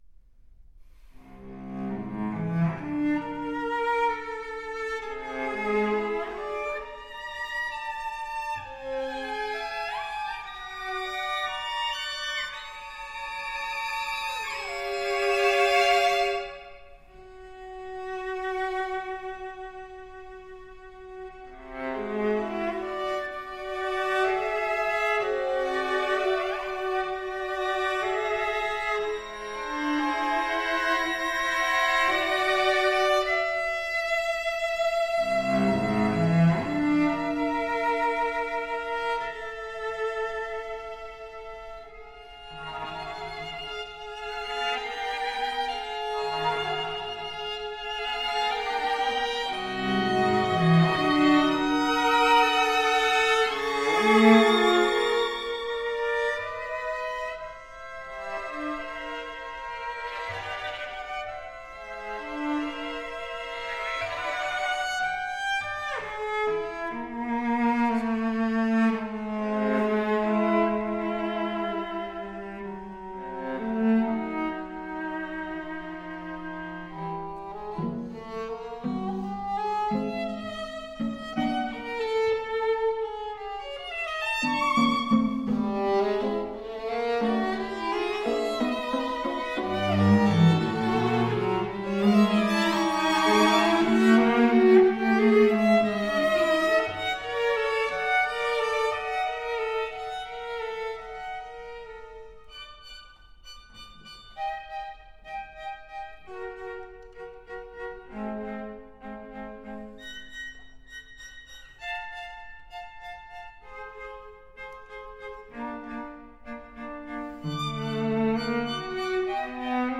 Larghetto